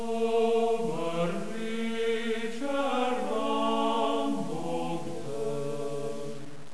The songs were sung by different choirs as: Ljubljanski oktet, Slovenski komorni zbor, Deseti brat oktet,